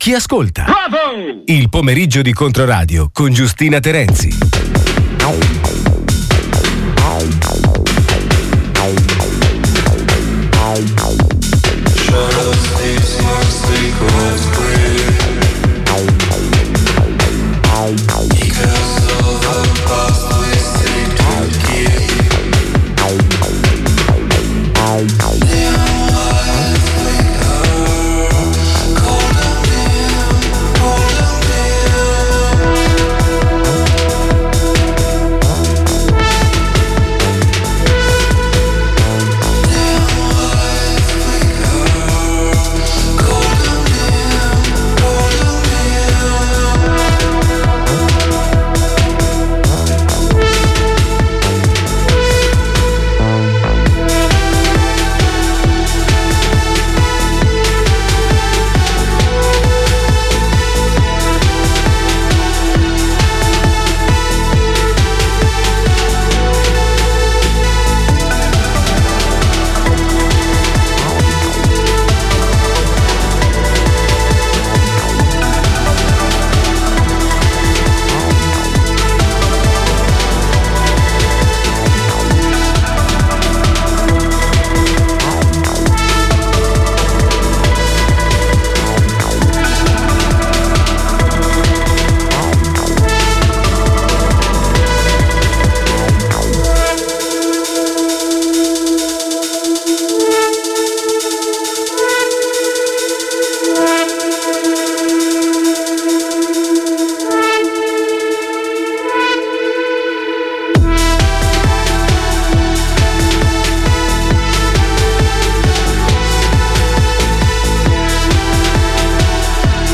L'intervista 🎧